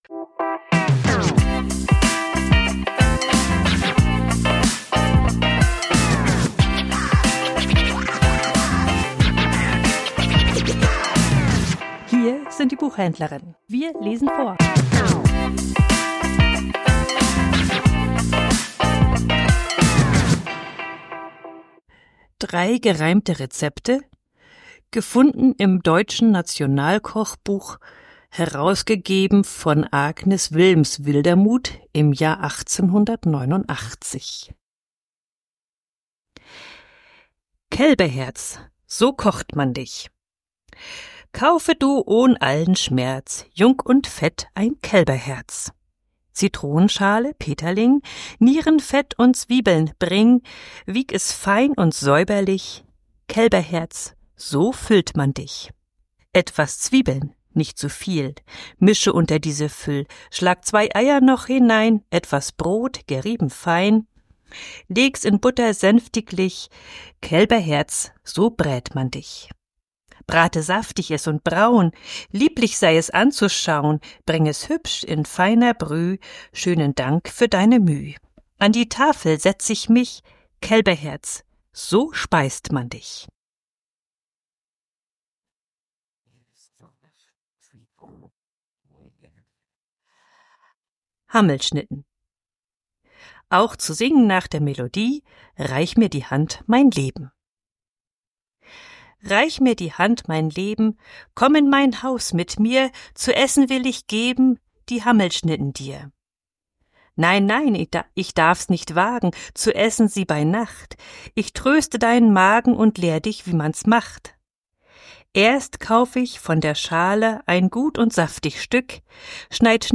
liest 3 gereimte Rezepte